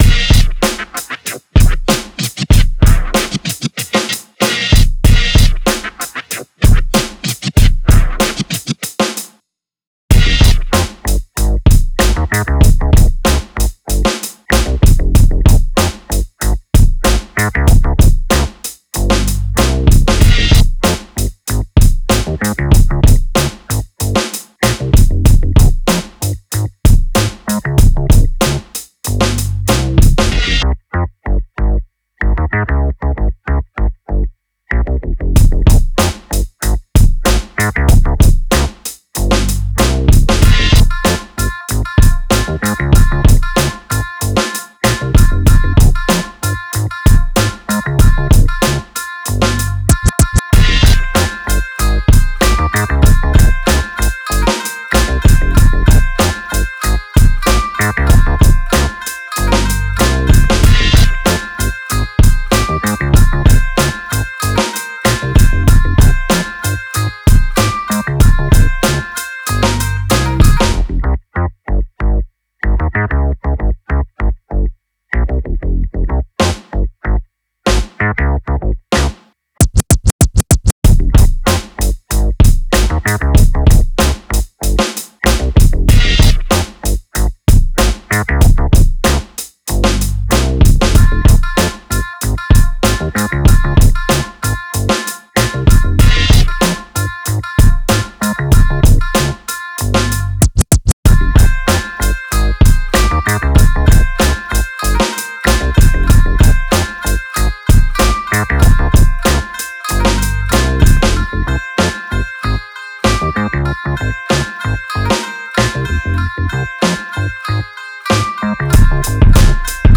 クラシカルなブレイクビーツにグッドなグルーヴを生み出すファンキーなベースラインを融合させました。
• BPM95
• Key Cm
• 構成　イントロ4 バース16 フック8 バース16 フック16 アウトロ4